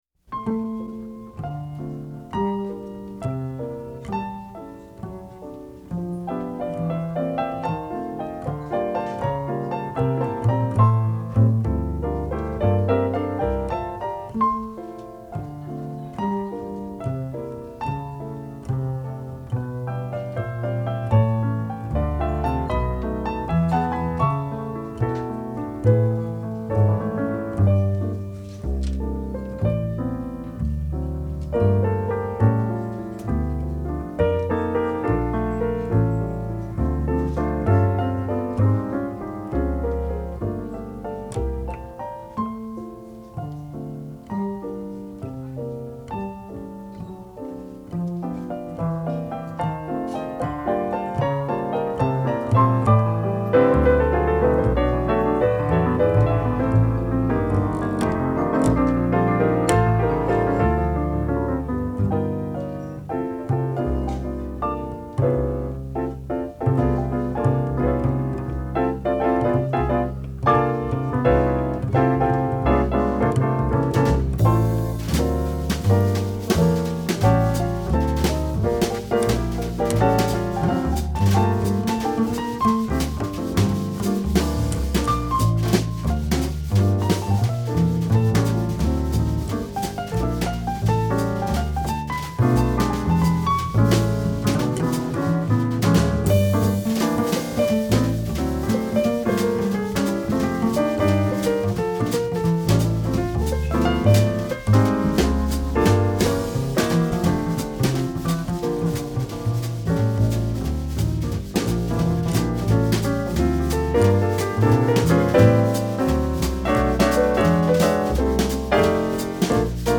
Genre : Jazz